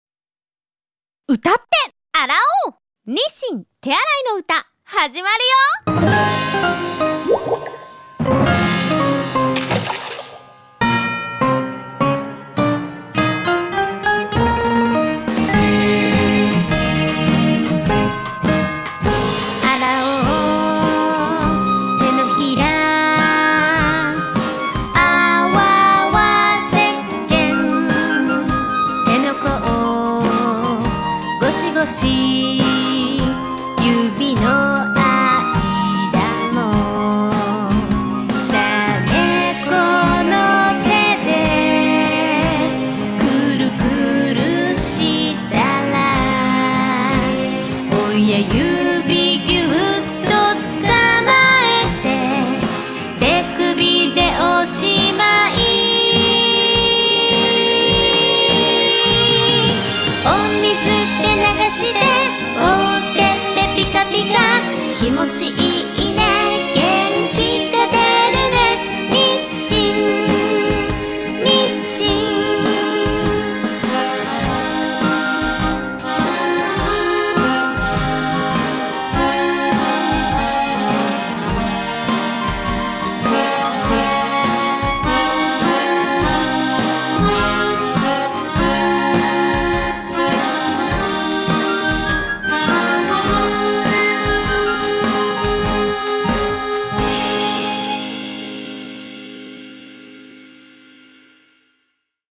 にっしん手あらいの歌（1番）スローテンポ (音声ファイル: 2.8MB)
tearaislow.mp3